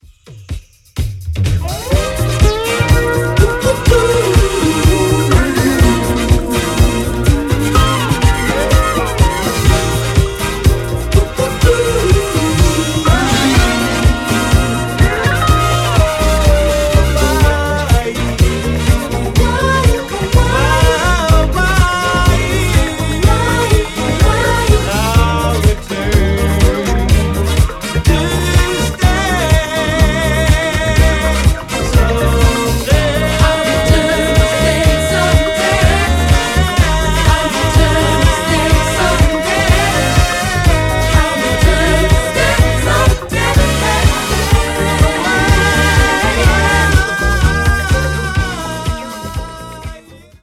Bringing you soulful, energetic dancefloor moments.
scorching disco edits